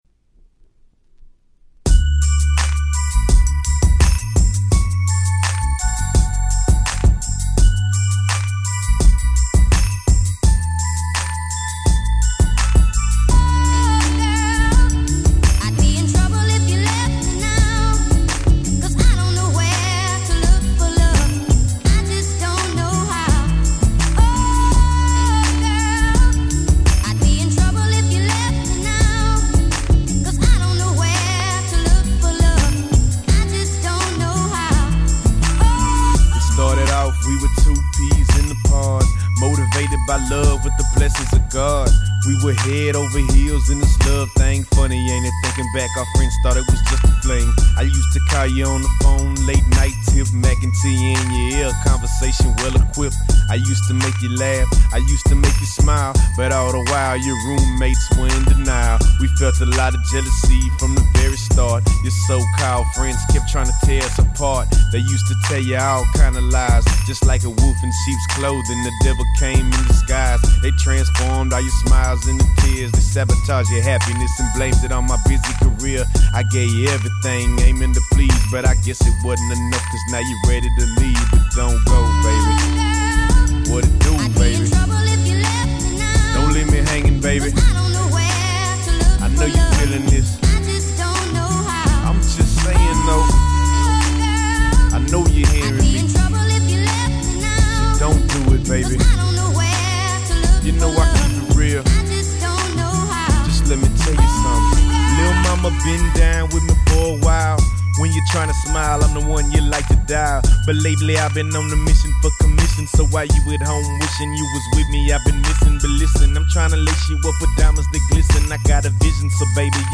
05' Smash Hit Southern Hip Hop !!